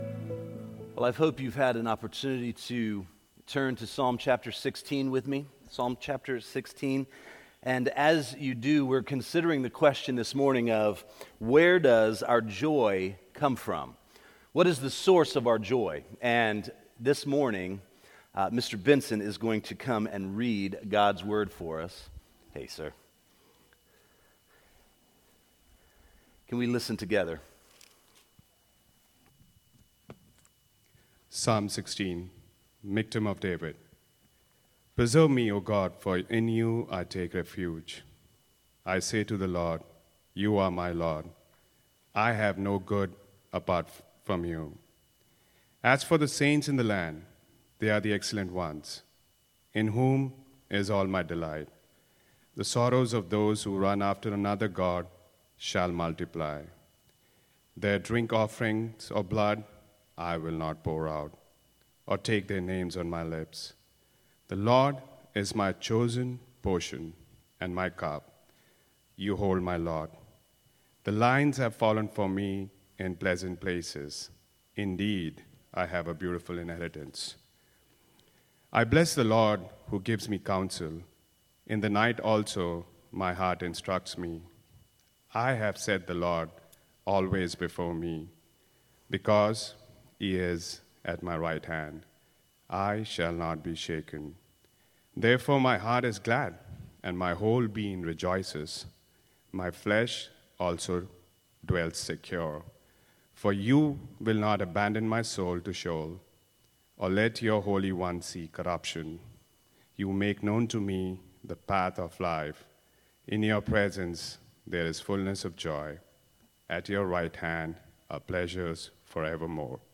Metro Life Church Sermons
This page contains the sermons and teachings of Metro Life Church Casselberry Florida